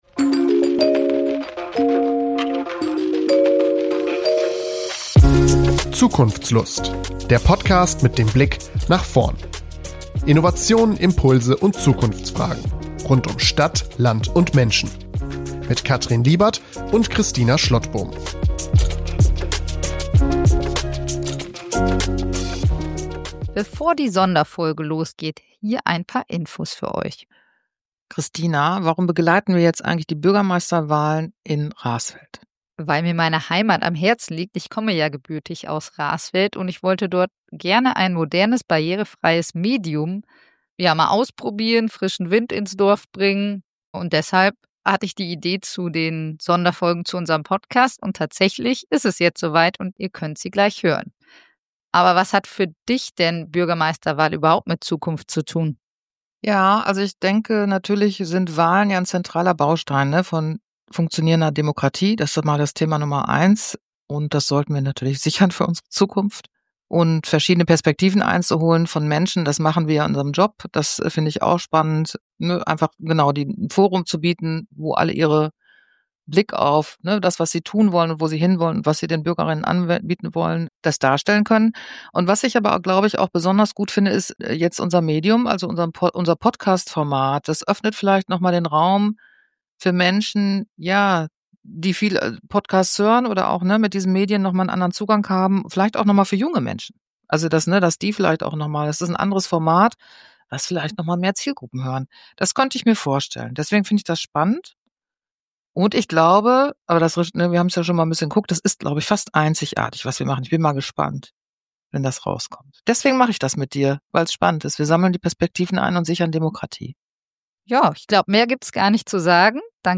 Ein persönliches Gespräch über Verantwortung, Veränderung und die Zukunft unserer Gemeinde.